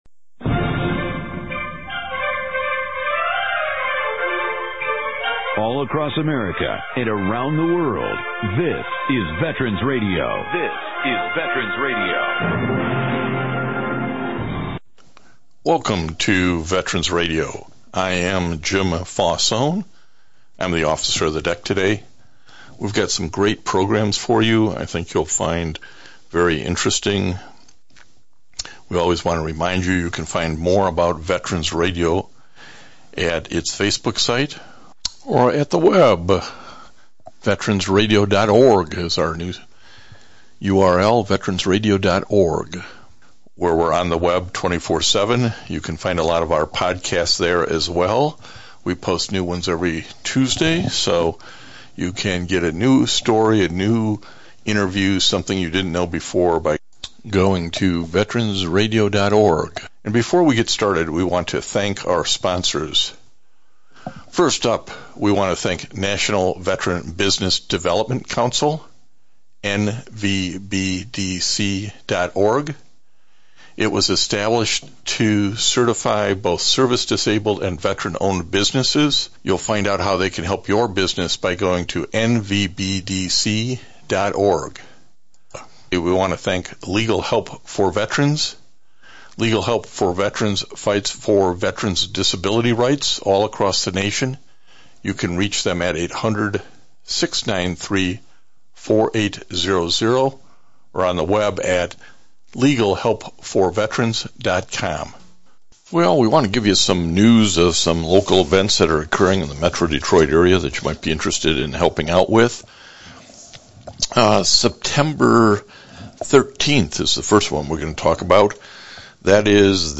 one hour radio broadcast